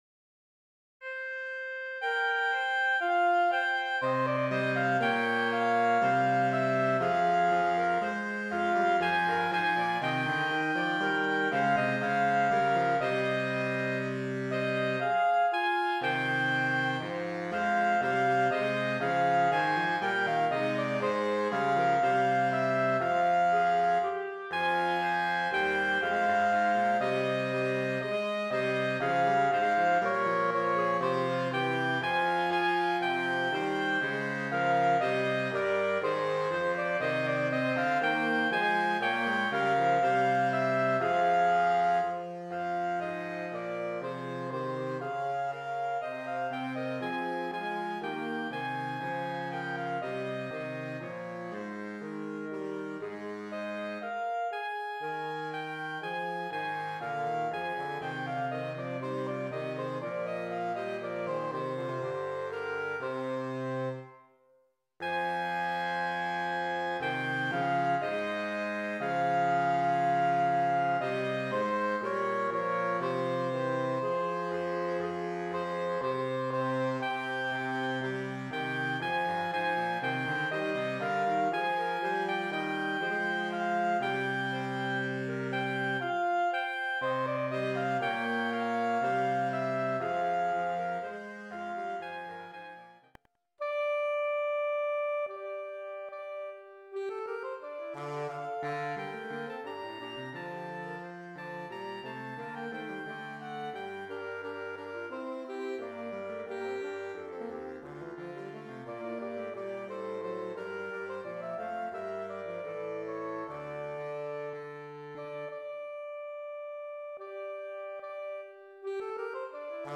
Voicing: Saxophone Quartet (SATB)